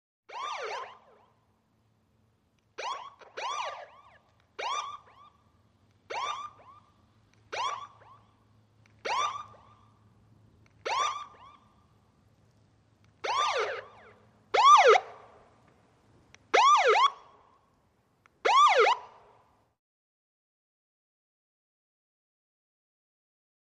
Short Whooper Siren Bursts, Moving From Medium Perspective To Close, With Echo.